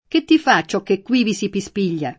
pispigliare v.; pispiglio [piSp&l’l’o], ‑gli — ant. voce onomatopeica, oggi d’uso lett.: var. meno com. di bisbigliare, con qualche sfumatura in più — es.: Che ti fa ciò che quivi si pispiglia? [
k% tti f# ©©q kke kkU&vi Si piSp&l’l’a?] (Dante)